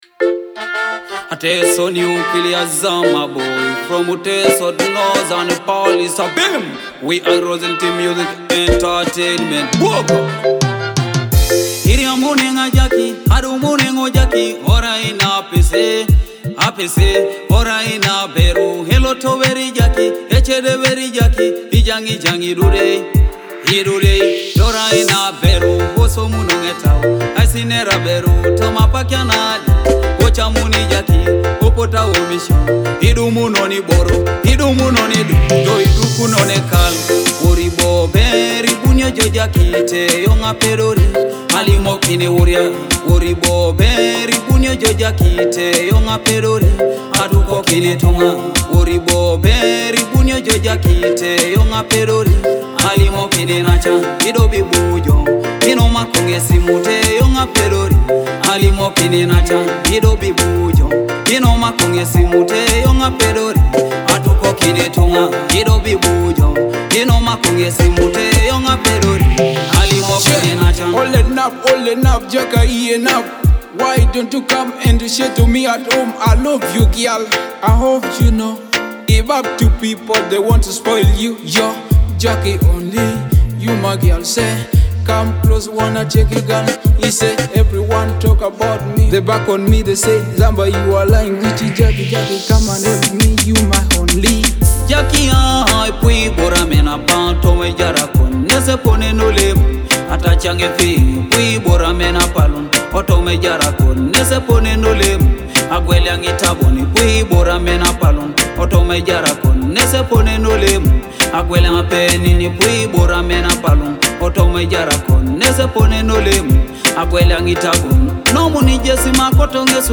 love song
delivered with smooth vocals and vibrant production.